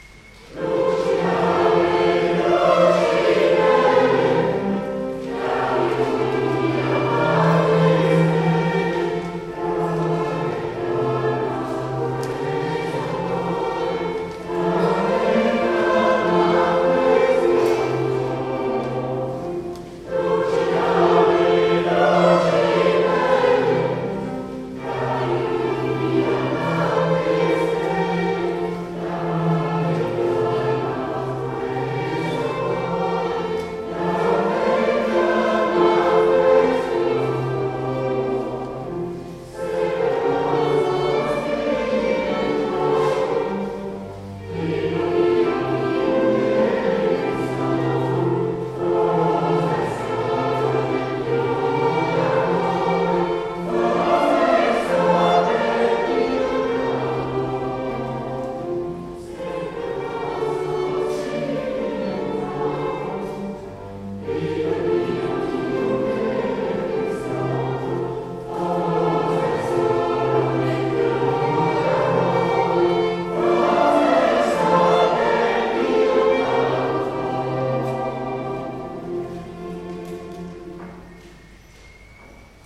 9 Novembre Eglise du Val d'Ajol Concert avec le quatuor à cordes Alliance